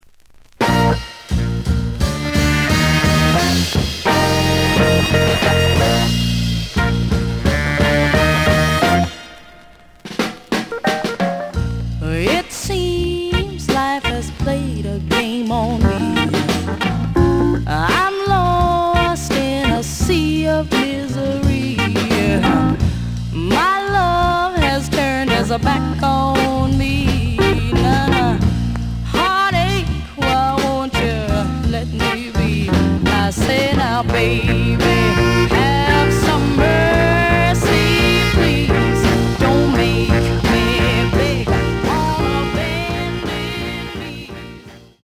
The audio sample is recorded from the actual item.
●Genre: Soul, 60's Soul
A side plays good.